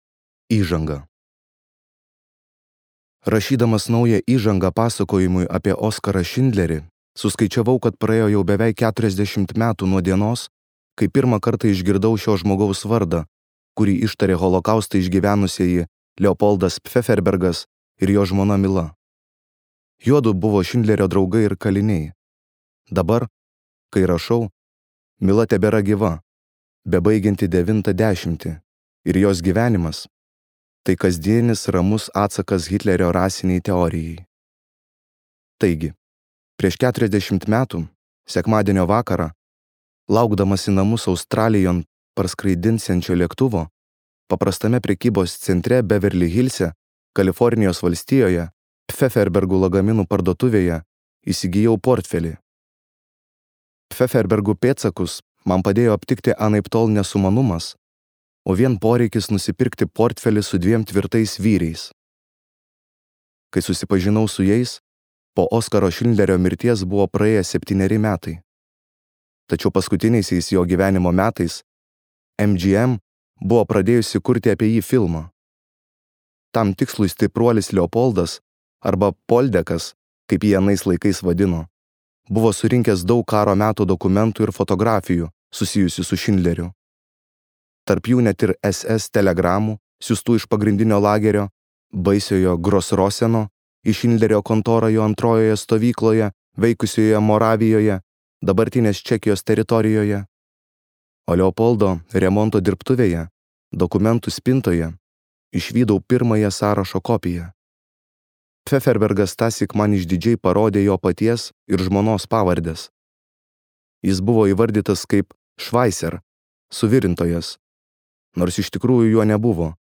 Šindlerio sąrašas | Audioknygos | baltos lankos